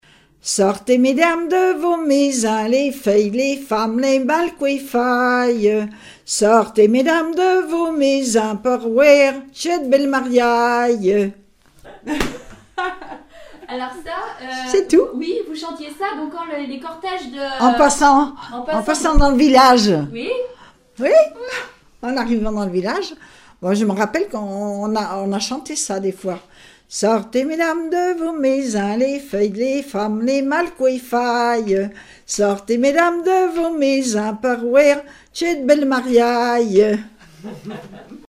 Enumératives - Nombres en décroissant
circonstance : fiançaille, noce
Répertoire de chansons populaires et traditionnelles